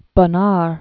(bô-när), Pierre 1867-1947.